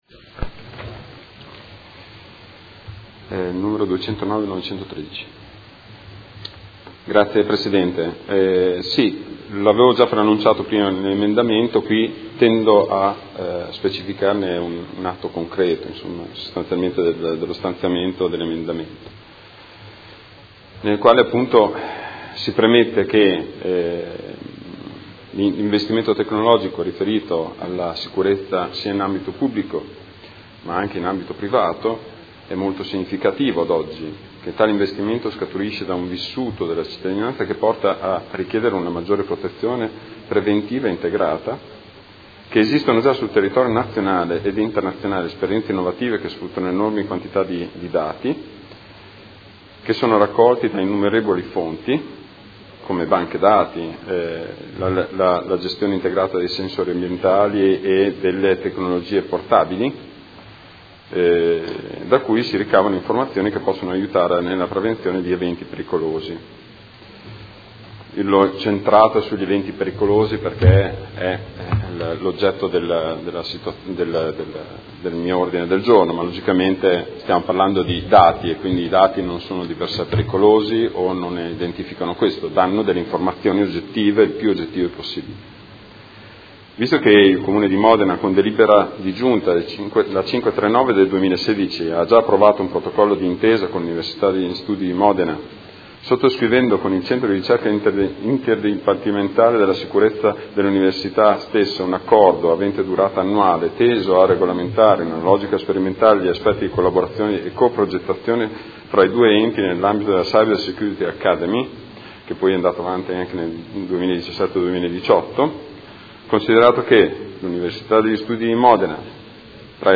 Seduta del 20/12/2018. Presenta Ordine del Giorno Prot. Gen. 209913